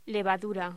Locución: Levadura
voz